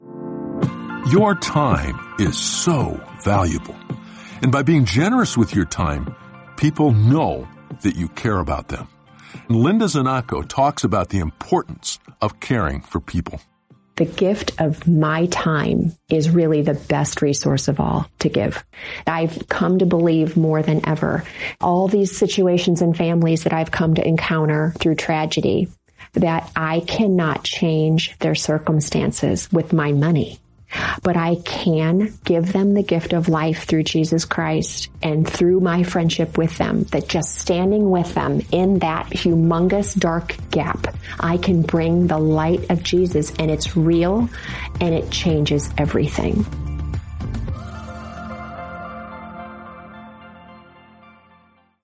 Each day, you’ll hear a short audio message with simple ideas to help you grow in your faith.